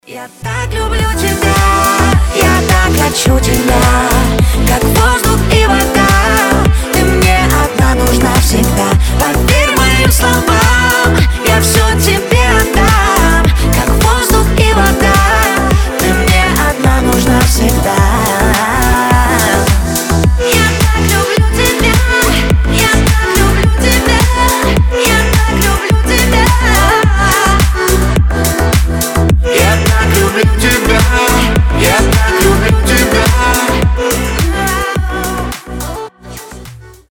• Качество: 320, Stereo
Cover